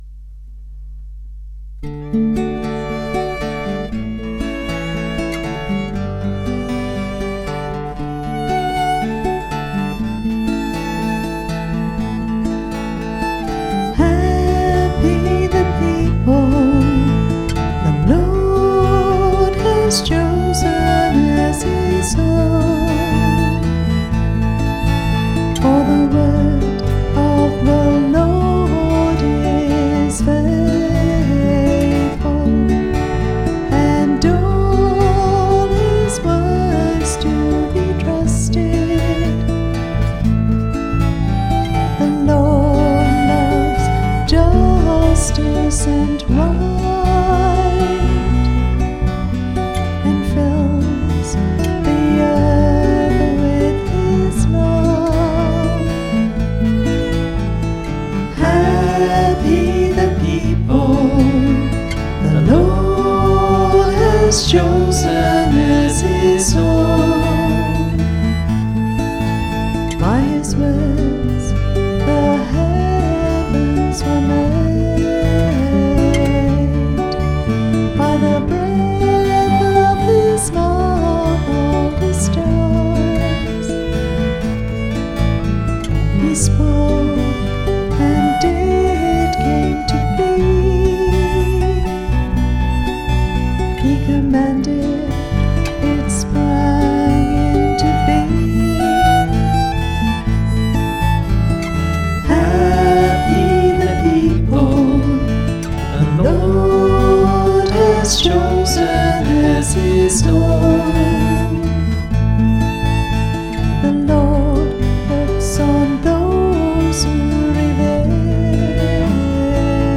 Responsorial psalm lyrics taken from the English translation and chants of The Roman Missal © 2010, International Commission on English in the Liturgy Corporation.
Music by the Choir of Our Lady of the Rosary RC Church, Verdun, St. John, Barbados.